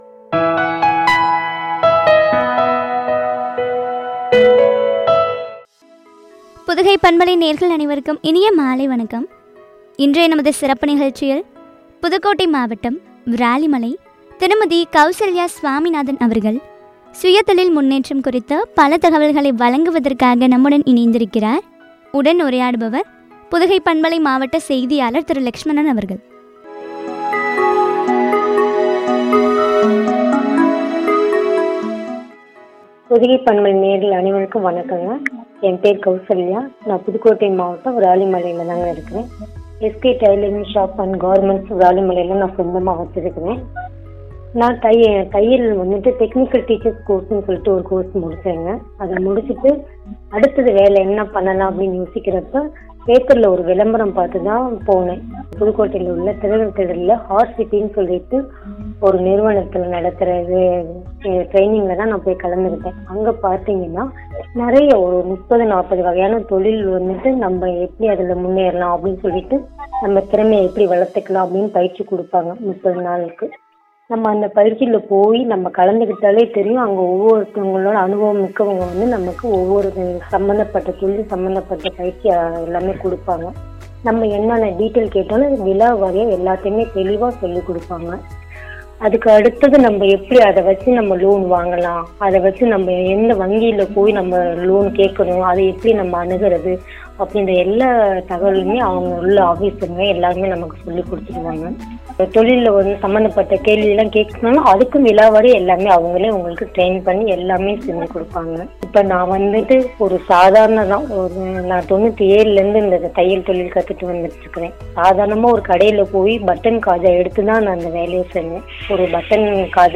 உரையாடல்.